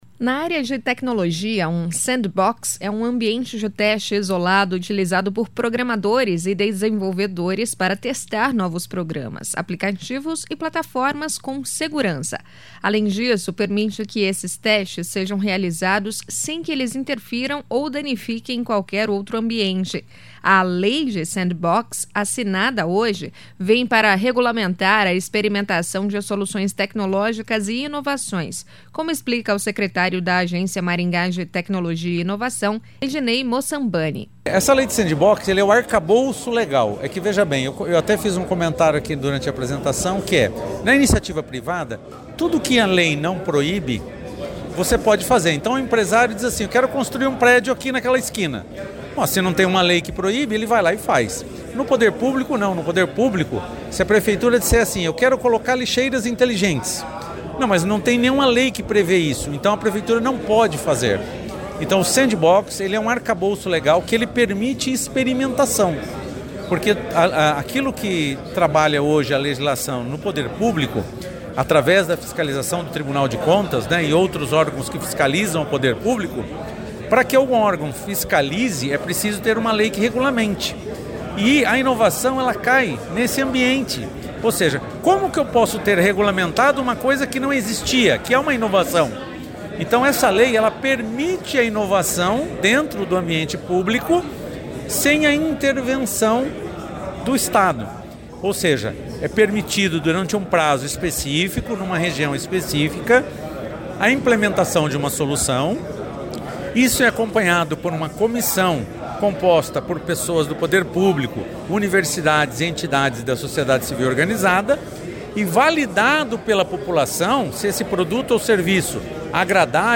O prefeito Ulisses Maia comentou sobre a união de esforços entre Maringá e Londrina para o desenvolvimento tecnológico.